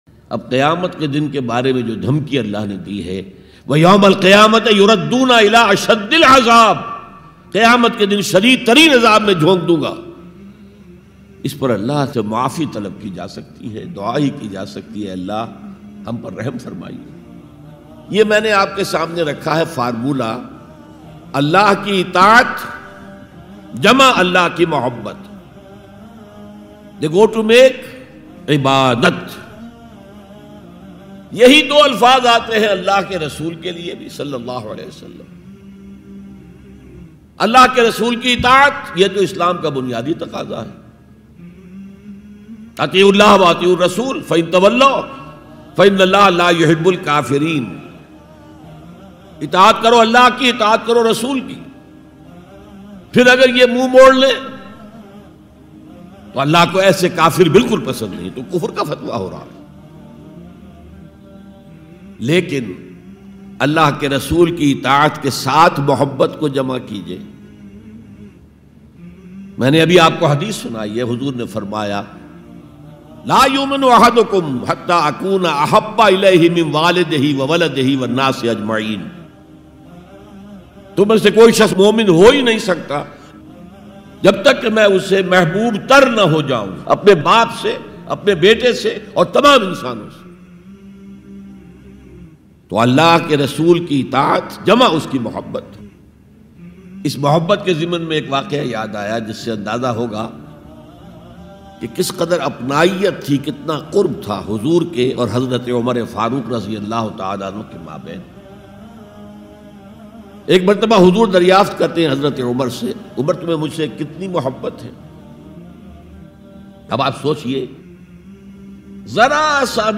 ALLAH Ki Mohabbat Hasil Karne Ka Tariqa Bayan MP3 Download By Israr Ahmad
Dr Israr Ahmed R.A a renowned Islamic scholar.